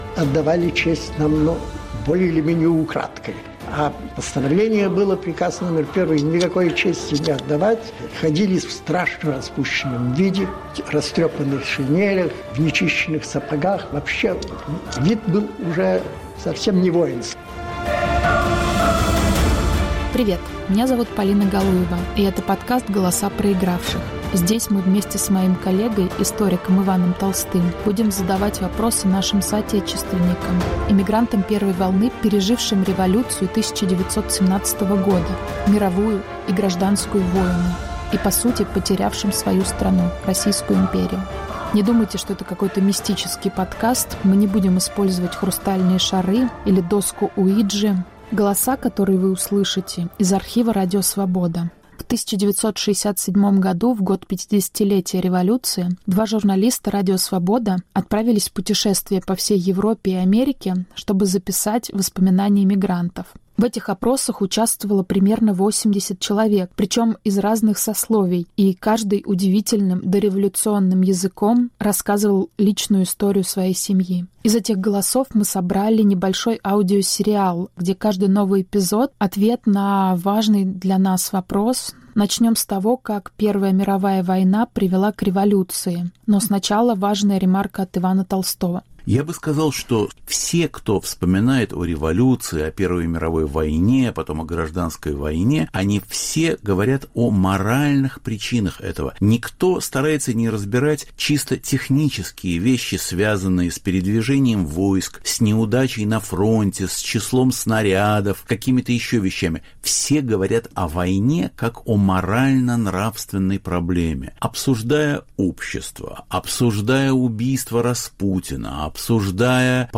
Воспоминания свидетелей Октября. Повтор эфира от 06 ноября 2022 года.